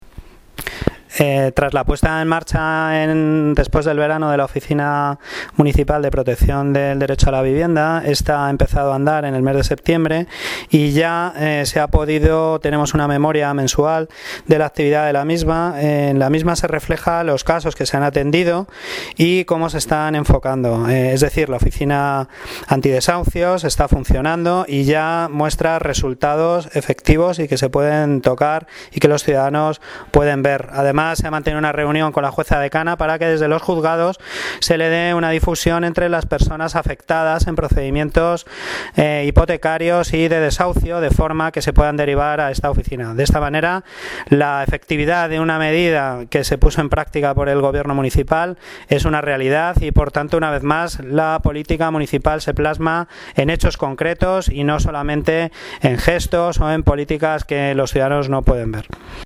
Audio - Eduardo Gutierrez (Concejal de Urbanismo y Vivienda) sobre oficina antidesahucios